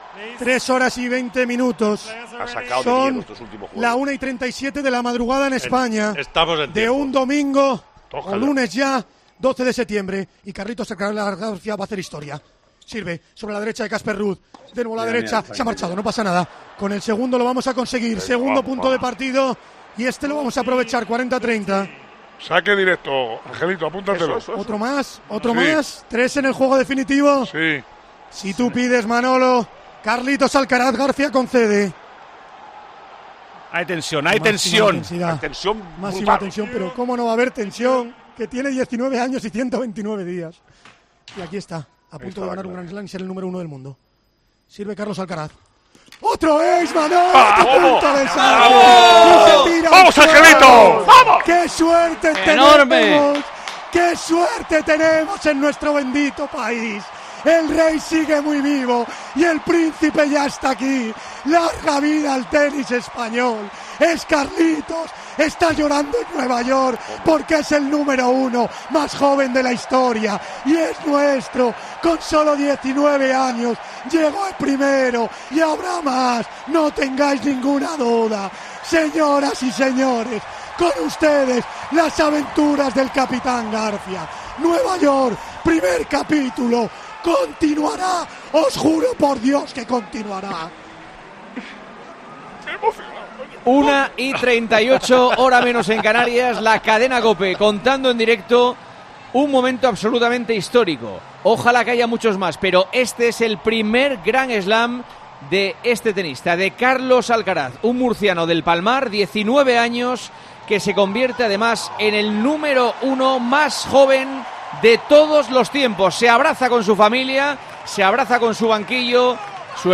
Así te narramos en Tiempo de Juego el histórico último punto de la final del US Open